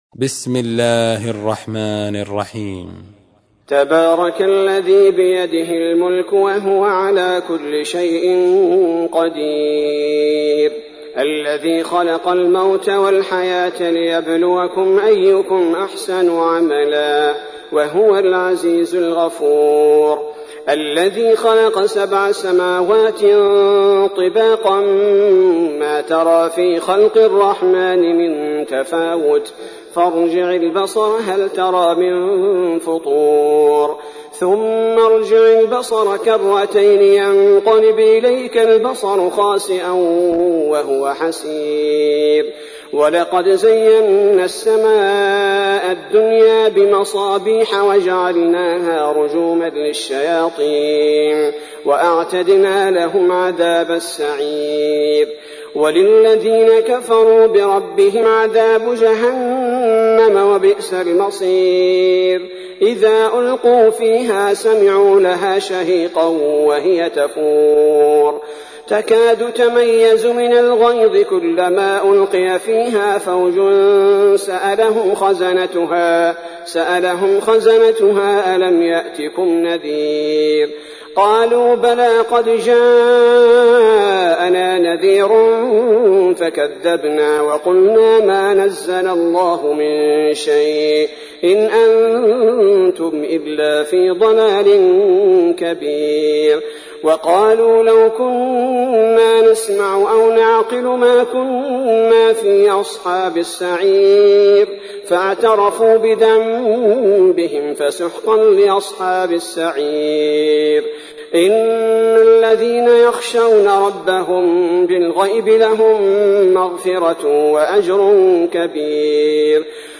تحميل : 67. سورة الملك / القارئ عبد البارئ الثبيتي / القرآن الكريم / موقع يا حسين